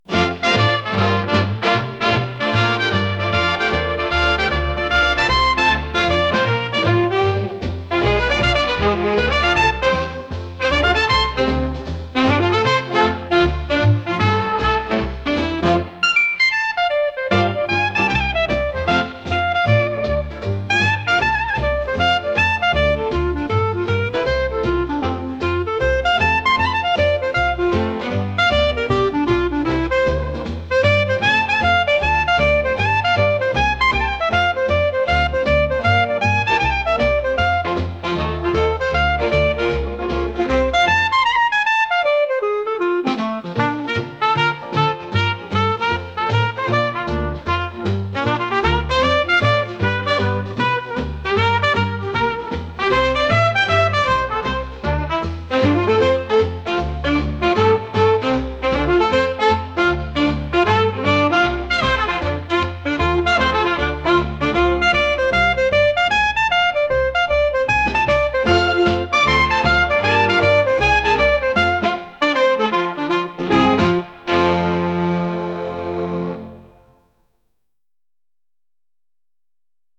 レトロなジャズ曲です。